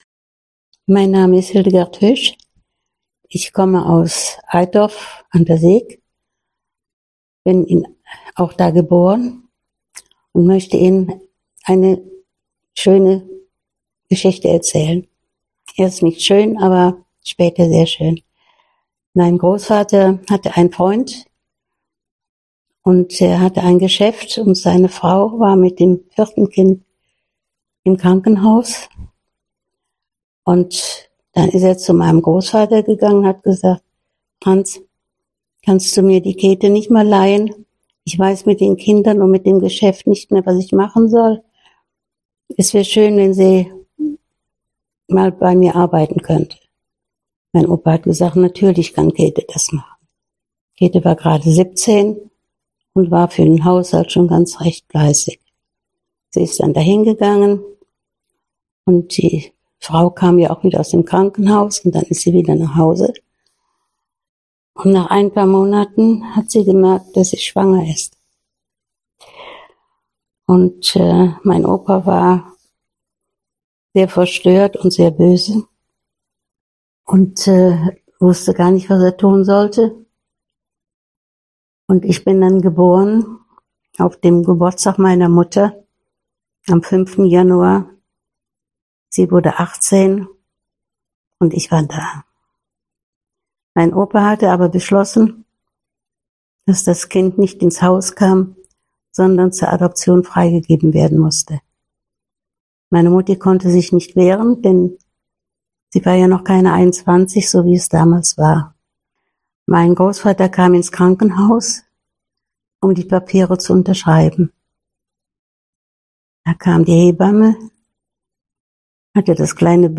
Bei den Aufnahmen, die oftmals in den Räumen der Waschbar stattfanden, ging es nicht um Perfektion, sondern um das wirkliche Leben. So begleiten schon mal Kirchenglocken oder auch Baustellengeräusche das gesprochene Wort.